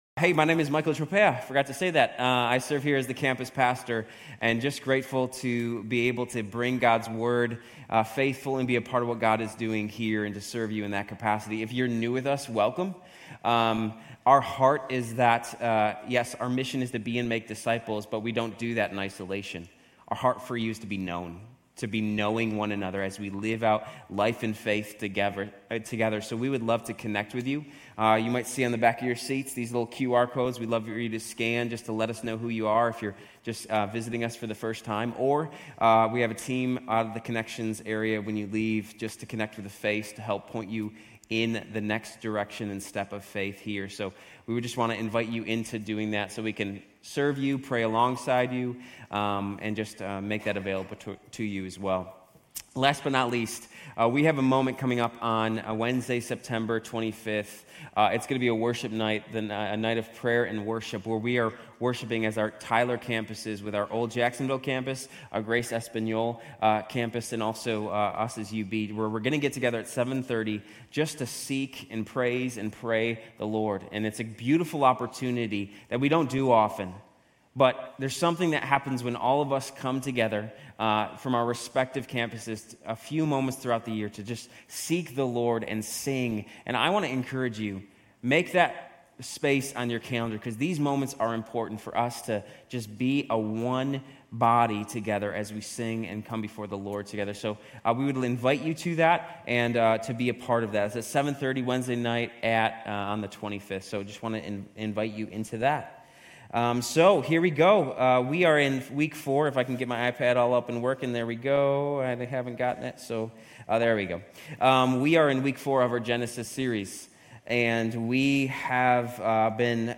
Grace Community Church University Blvd Campus Sermons Genesis 1:26-28, 2:21-25 - Gender & Gender Roles Sep 09 2024 | 00:42:13 1x 00:00 / 00:42:13 Subscribe Share RSS Feed Share Link Embed